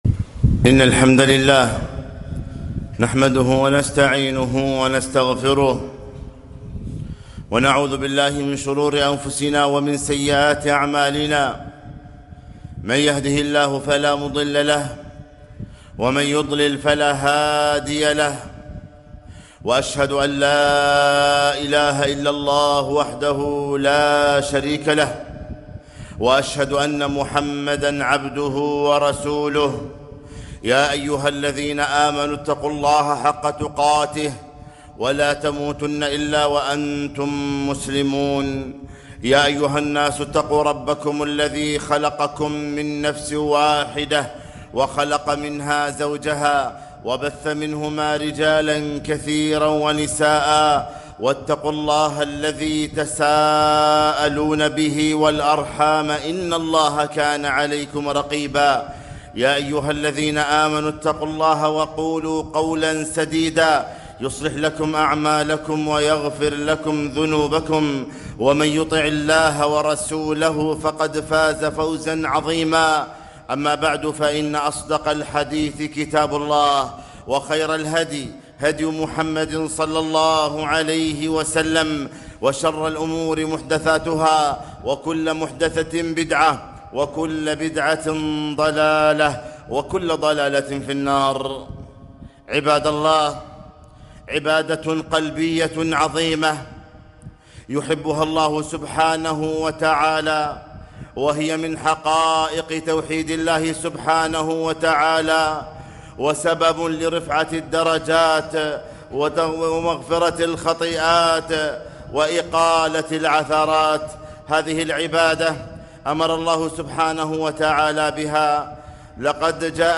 خطبة - أحسنوا الظن بالله